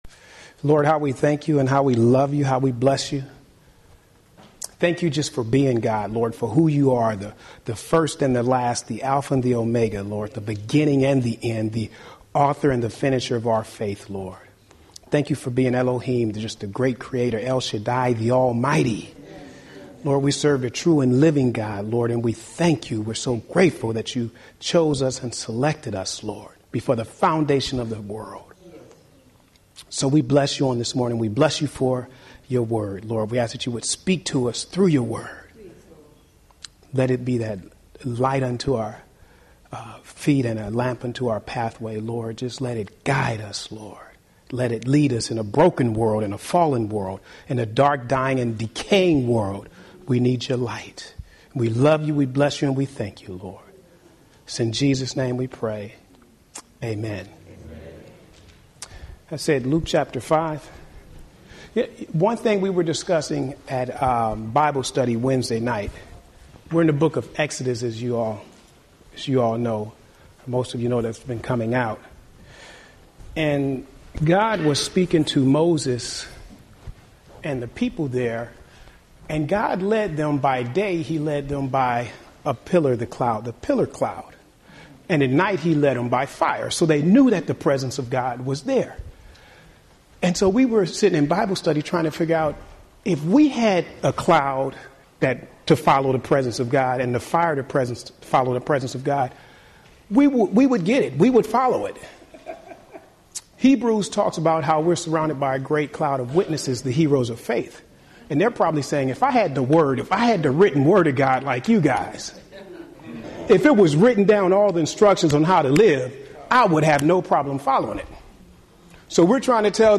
Home › Sermons › Communion Healing and Forgiveness